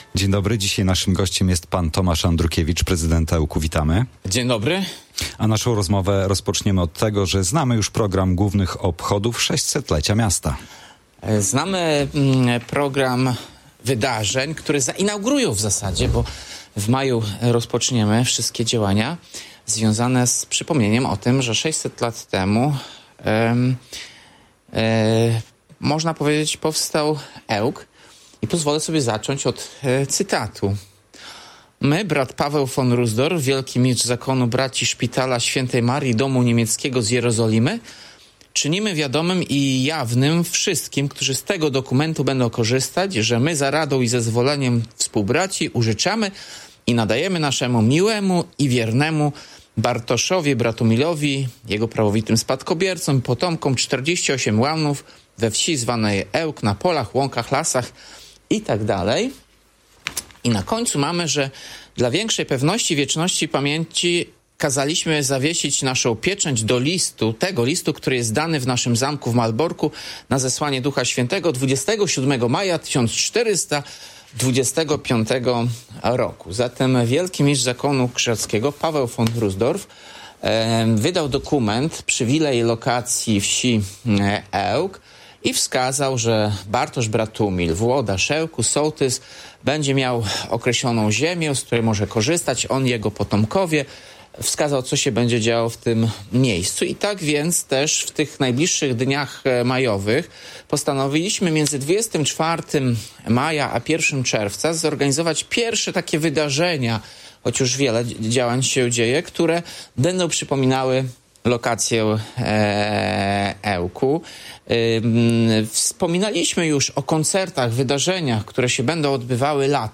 Tomasz Andrukiewicz, prezydent Ełku